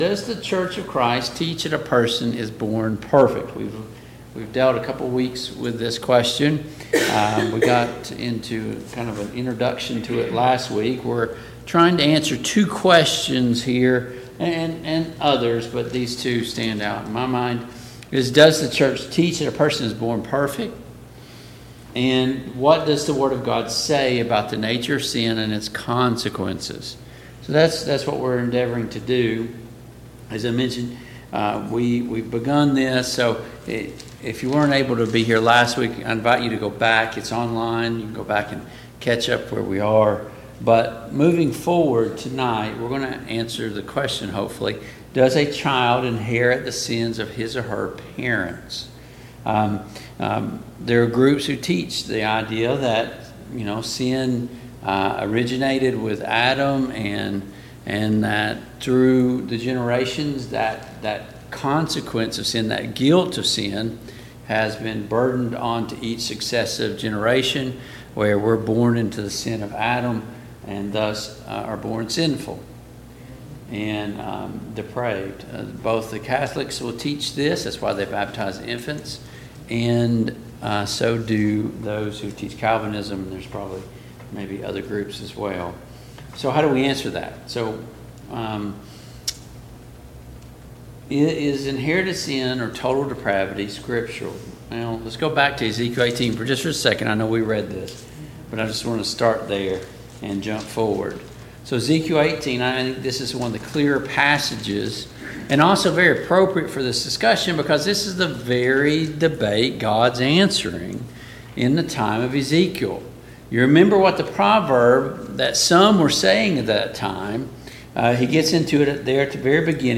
Service Type: Mid-Week Bible Study